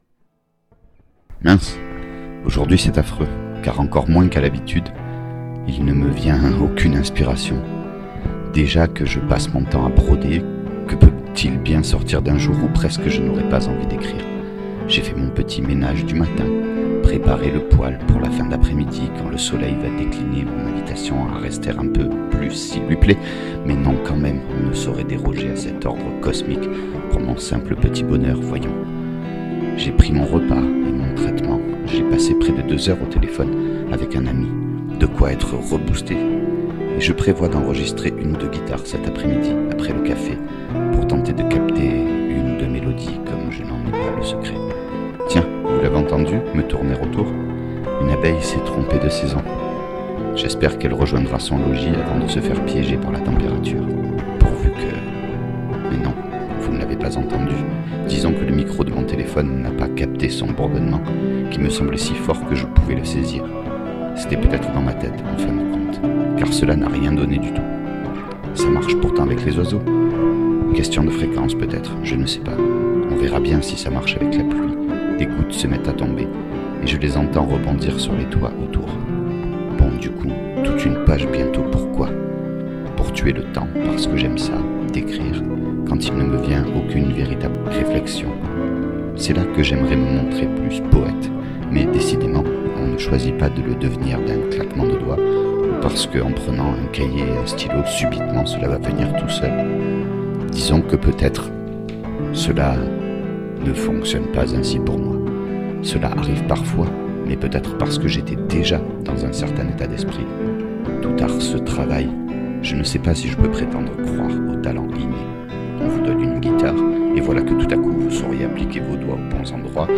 Le principe est tout simple : Un texte, comme une chronique, rédigé(e) avec un stylo sur un cahier (parce que c’est comme ça que j’écris), lu(e) devant un micro et accompagné(e) d’une improvisation à la guitare.
De plus je ne dispose pas d’un excellent matériel (un vieil ordi, une carte son externe M-Audio, un micro chant et une guitare) et je pense pouvoir améliorer la qualité du son, avec le temps et un peu d’investissement, mais prenez plutôt cet exercice comme une expérimentation de ma part, vous serez moins déçu(e)s. A noter : Les dates correspondent aux phases d’écriture, pas à l’enregistrement de la musique, mise en boîte quand il m’en vient la motivation.